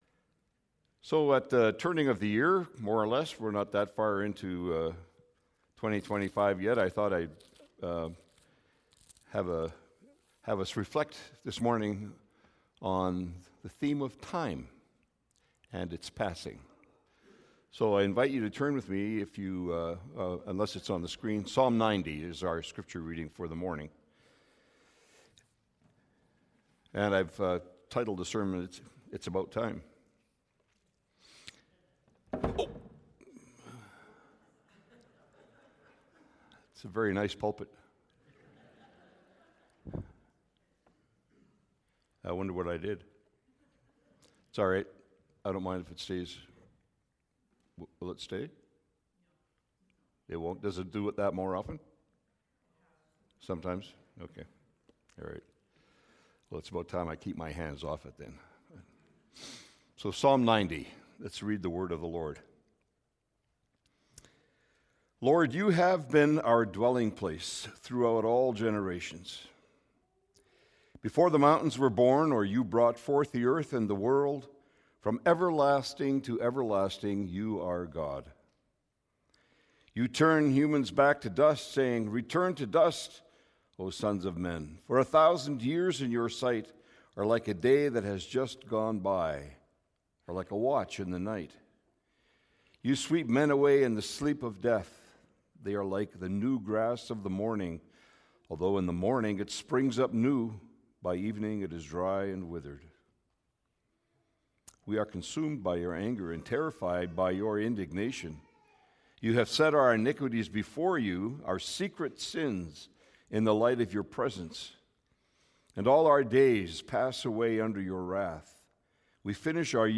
Sermons | Ebenezer Christian Reformed Church